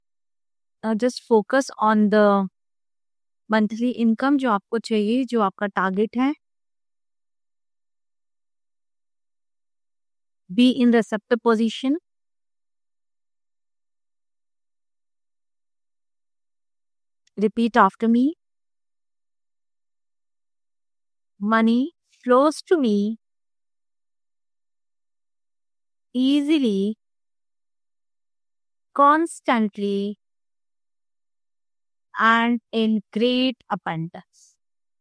• Guided Energy Activation – Experience a powerful affirmation session designed to align you with abundance